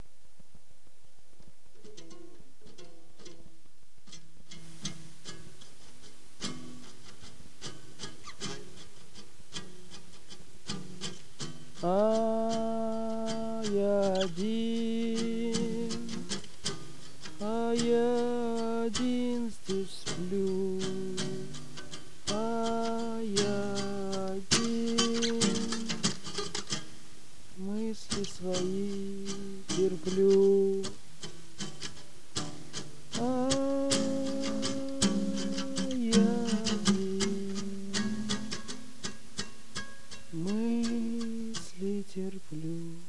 Мандолина и лежащий человек.